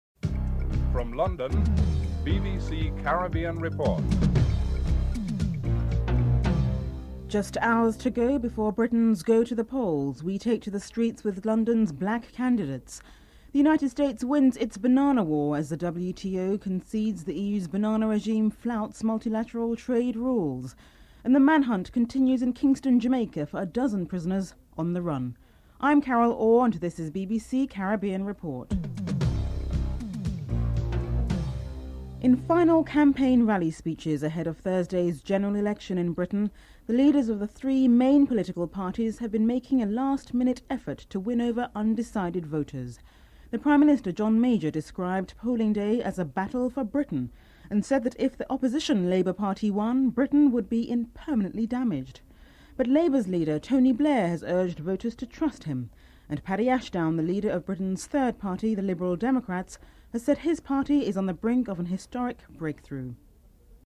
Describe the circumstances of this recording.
Final general election speeches in Britain as the three main political parties make a last minute effort to win over undecided voters. Caribbean Report takes to the streets with some of the black candidates in London.